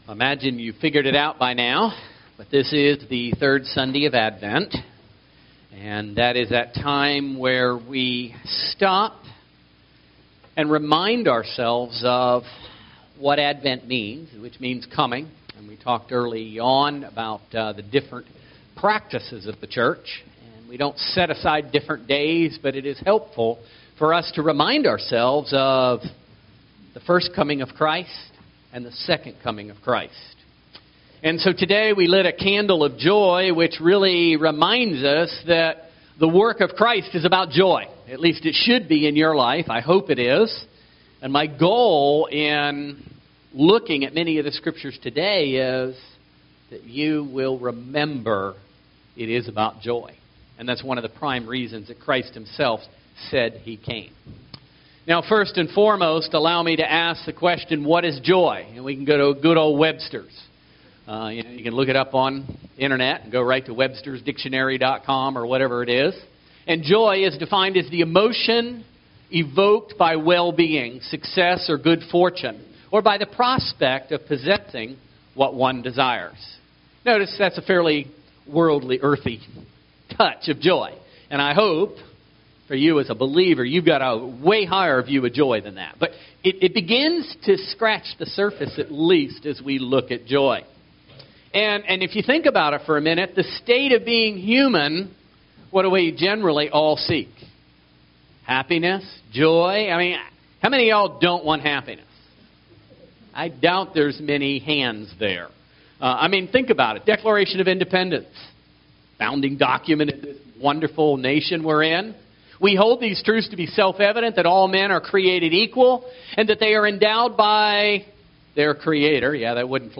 Service Type: Special non-Sunday service